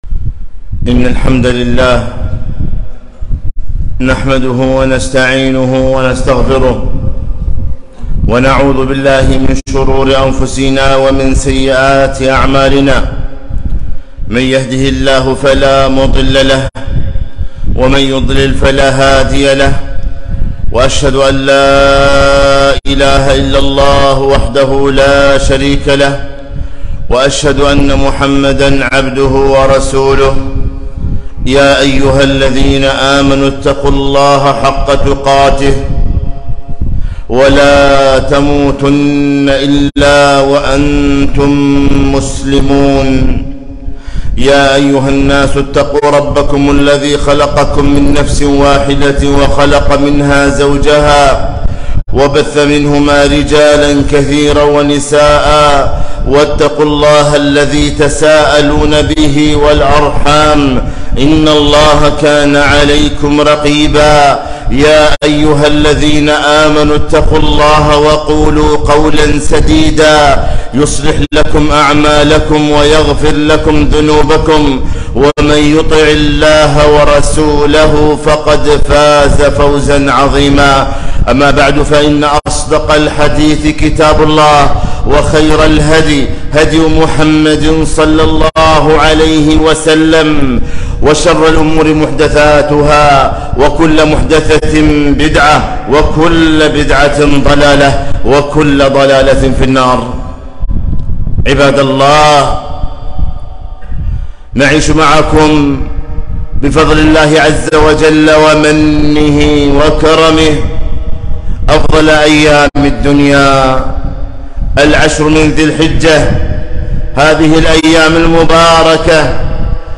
خطبة - خير يوم طلعت عليه الشمس